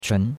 chun2.mp3